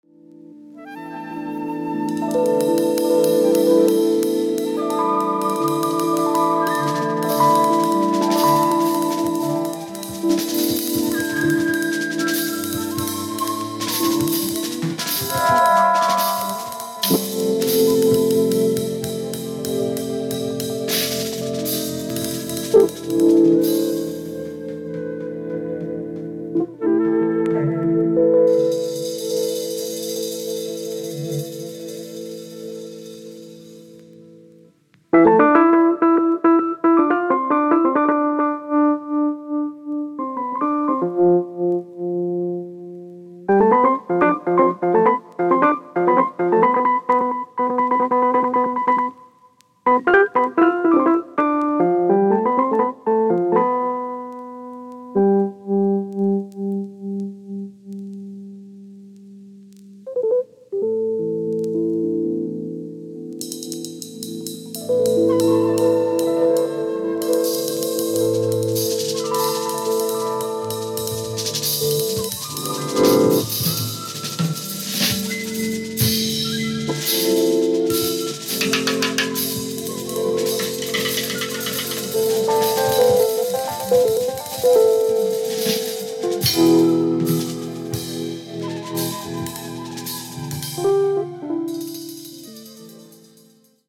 Electric Piano
Drums, Percussion
Double Bass
Saxophone